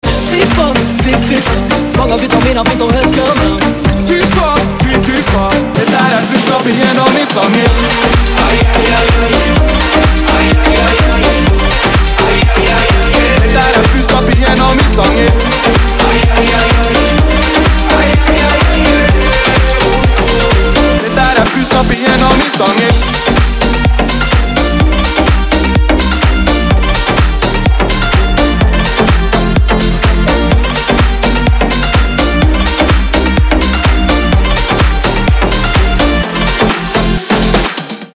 громкие
зажигательные
dance
Club House